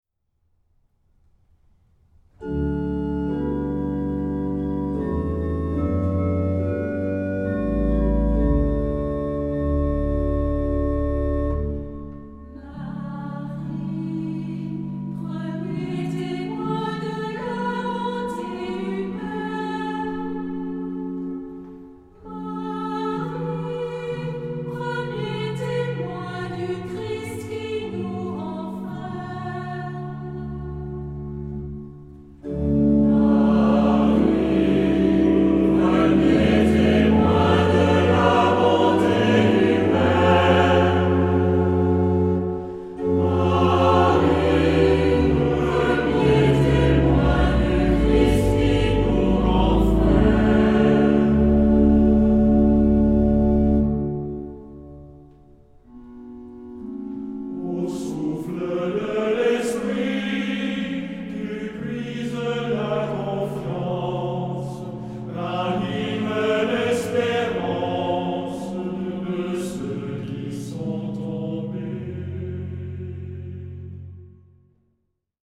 Genre-Style-Form: Canticle
Mood of the piece: meditative
Type of Choir: SATB  (4 mixed voices )
Instrumentation: Organ  (1 instrumental part(s))
Tonality: D major